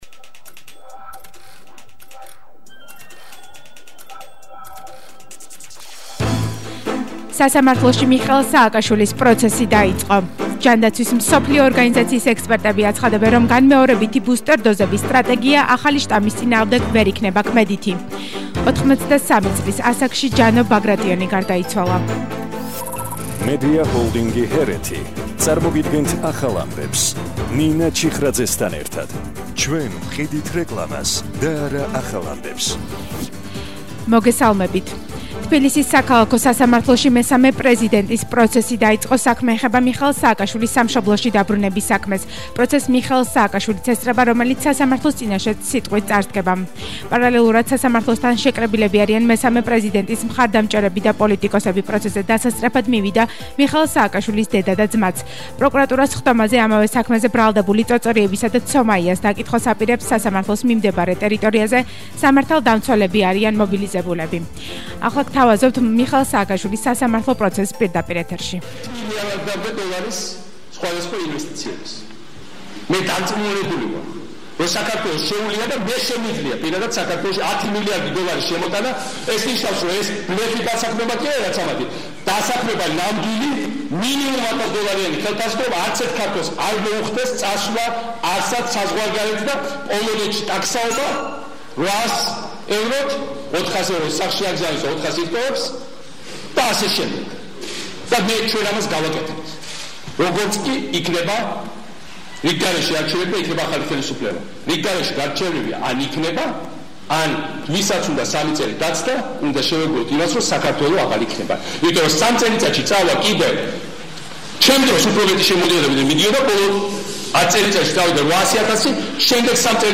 ახალი ამბები 14:00 საათზე – 13/01/22